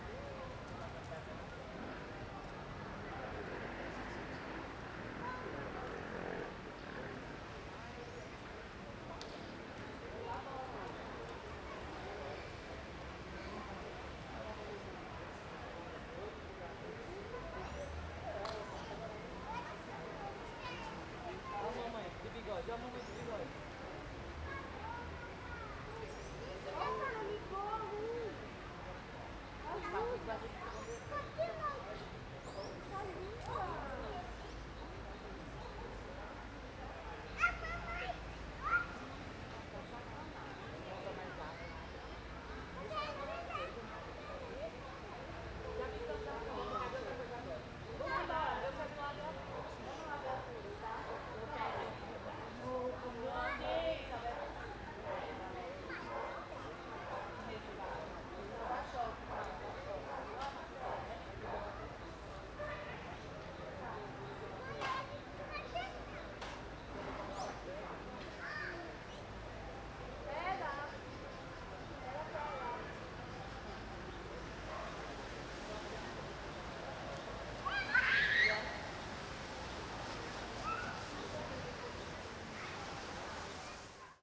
CSC-04-253-OL- Praca em Alto Paraiso no fim de tarde.wav